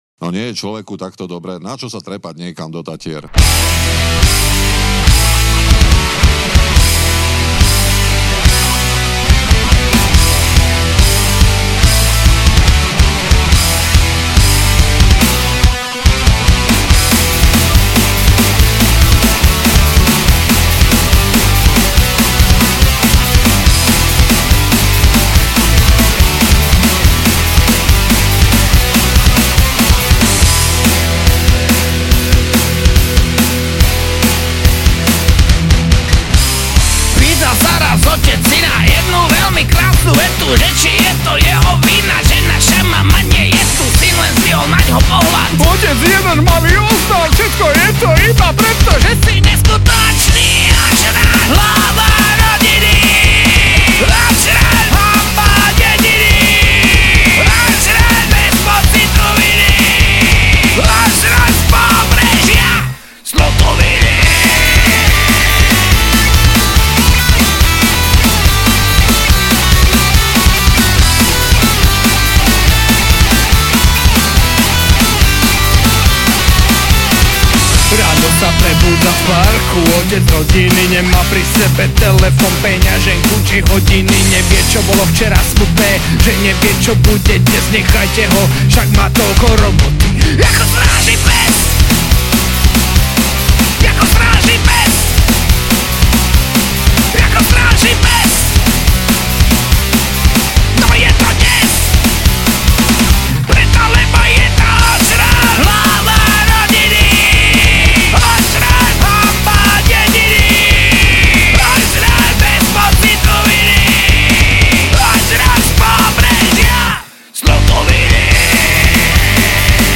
Žánr: Rock
gitara, spev
basgitara